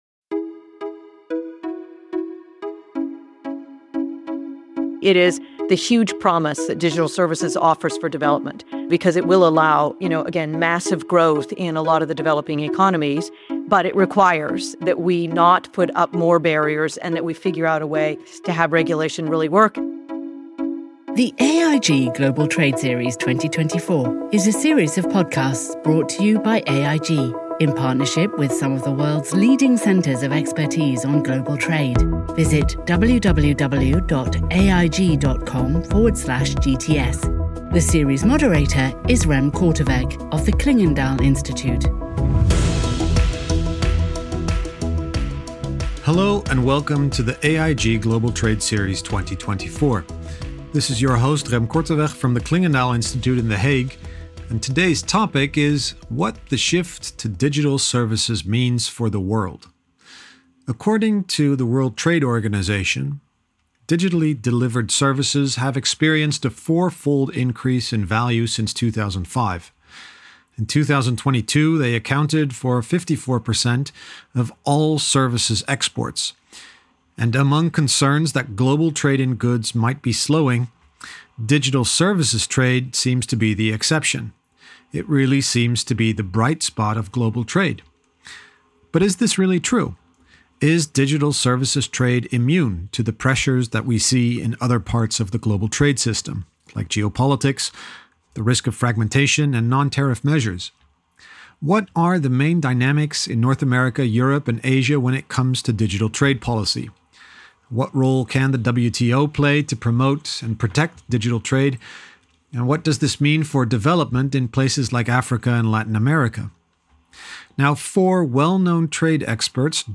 s5_ep6_wto_panel.mp3